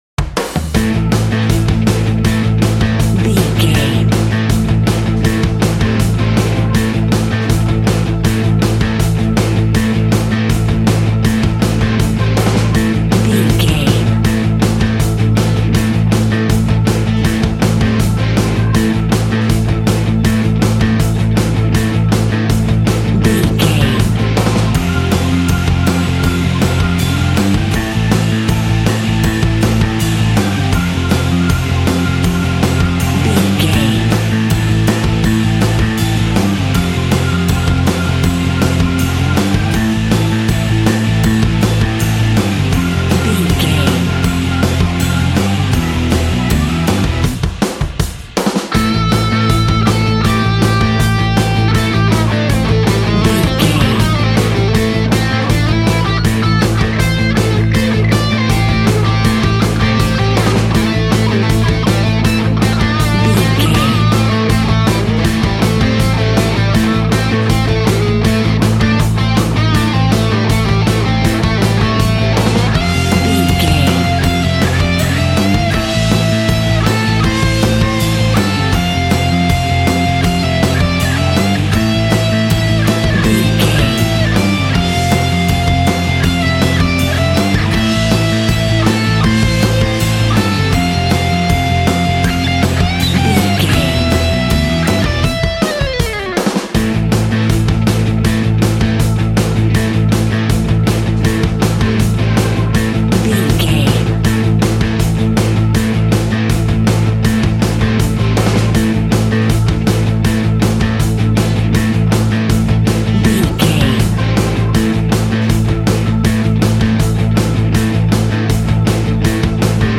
Aeolian/Minor
angry
heavy
aggressive
electric guitar
drums
bass guitar